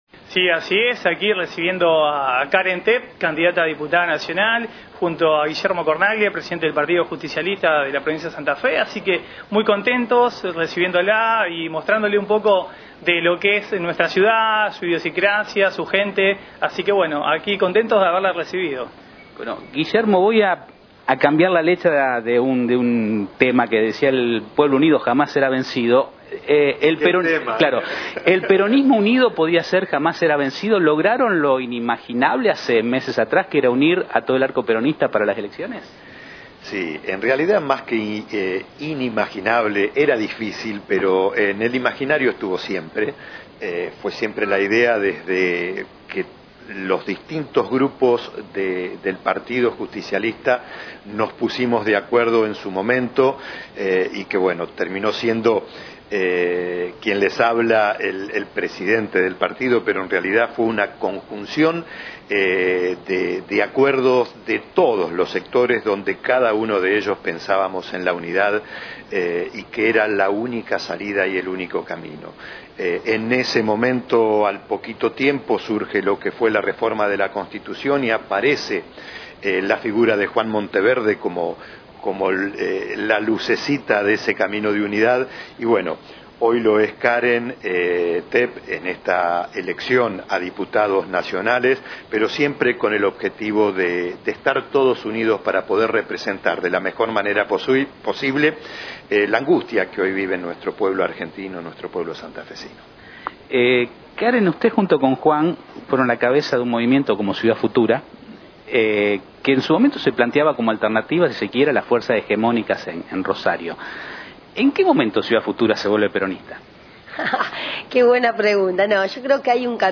Esta mañana visitó los estudios de Renacer Regional, Caren Tepp, candidata a diputada nacional por Santa Fe (Fuerza Patria), quien llegó a Las Rosas acompañada por el concejal Marcos Di Santo y el presidente del PJ provincial Guillermo Cornaglia.
CAREN TEPP – GUILLERMO CORNAGLIA – MARCOS DI SANTO Candidata a Dip. Nacional (Fuerza Patria)- Pte. PJ Provincial – Concejal